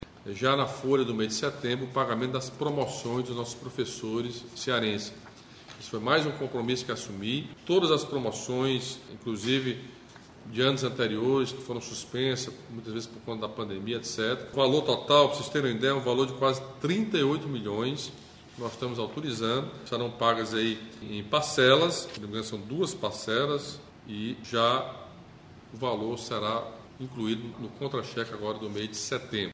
Em live semanal nas redes sociais, o governador Camilo Santana assinou, nesta terça-feira (24), um projeto de lei que transforma o Vale Gás Social em política pública permanente.